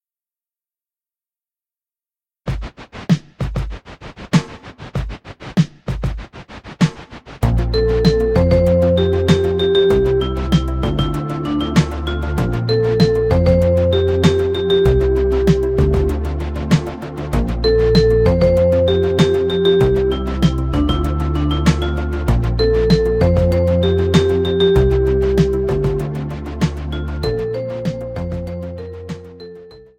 Voicing: Trombone and Audio Online